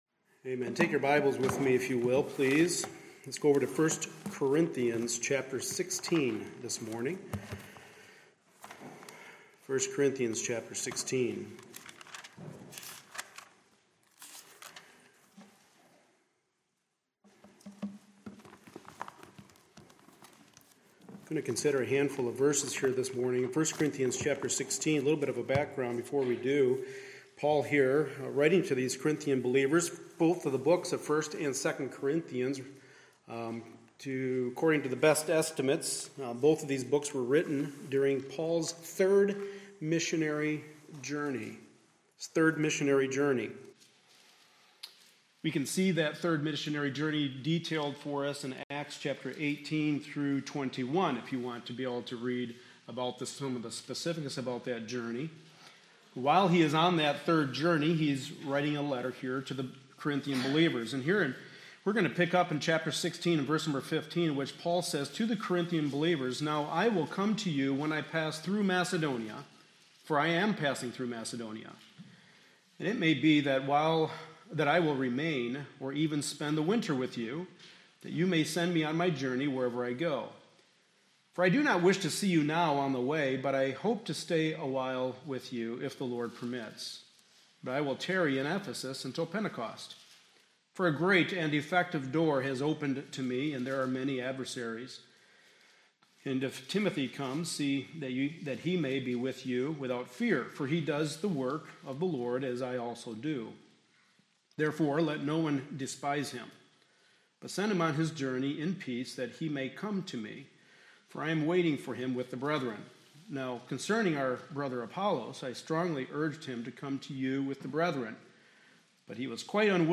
Passage: 1 Corinthians 16:5-9 Service Type: Sunday Morning Service Related « The Church’s Responsibility to the Pastor